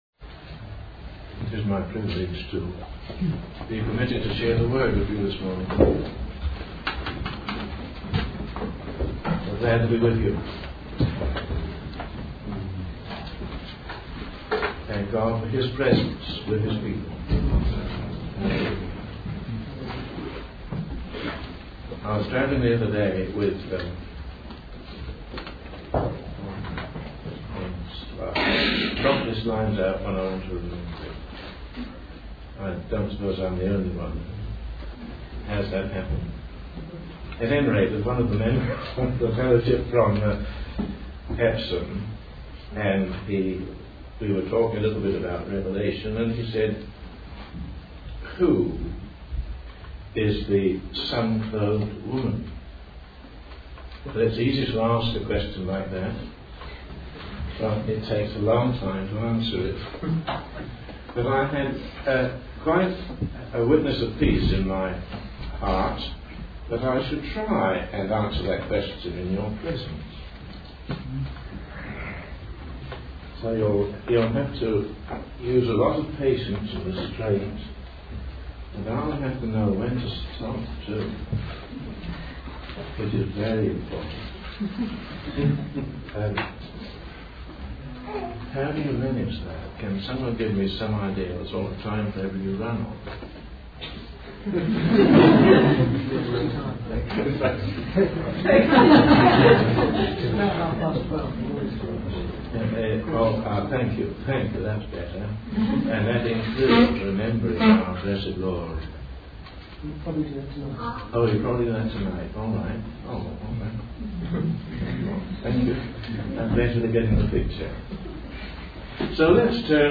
In this sermon, the speaker explores the identity of a woman mentioned in Revelation chapter 12.